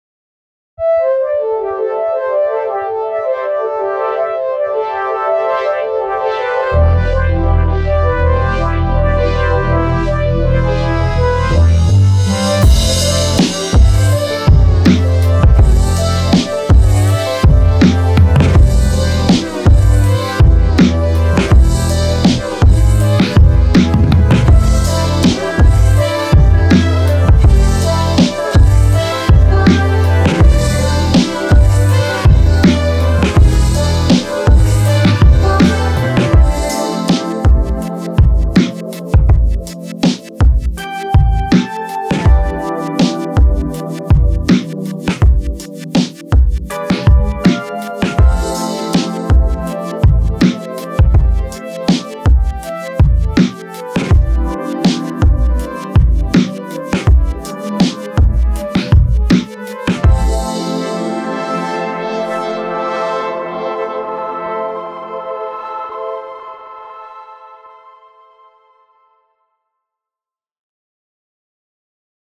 复古合成综合音源 Teletone Audio Ondine KONTAKT-音频fun
Teletone Audio Ondine 是一款基于 Kontakt 的虚拟乐器，它使用了一些世界上最具标志性的复古合成器的采样，创造出了一些轻盈而迷幻的声音。这款乐器被描述为 Scarbo 的前传，它探索了合成器声音的明亮和催眠的一面。
Ondine 提供了50多种乐器和25种多重音色，涵盖了键盘、垫音、低音、主音和合成器等各种声音。